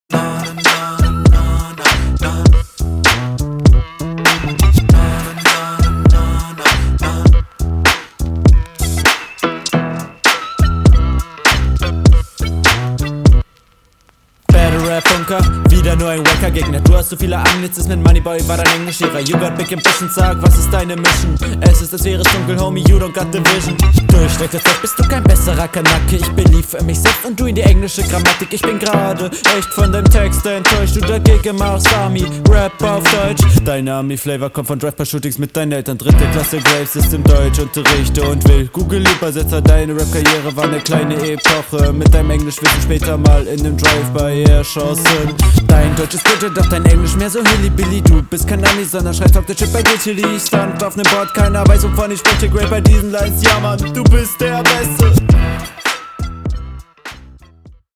hat nichts mit der runde zu tun aber der beat ist sick